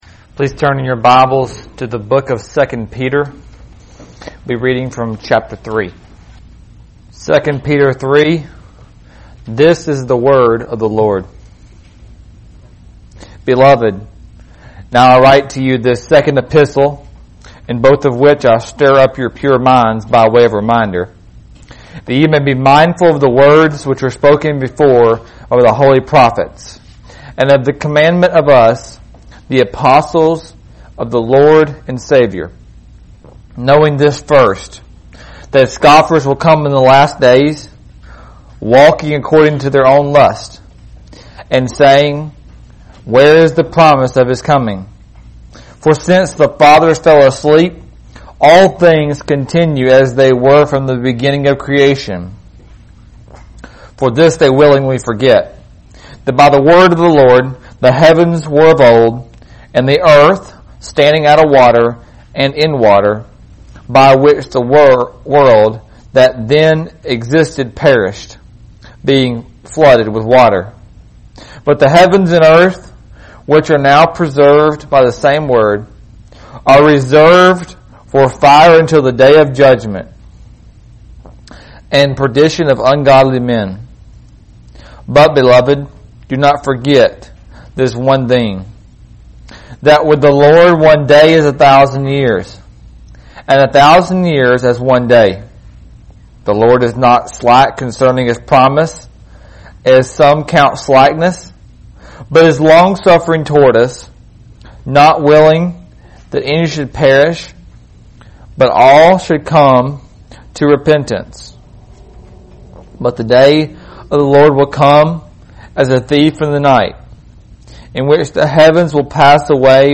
teaches on why believers cannot be idiots.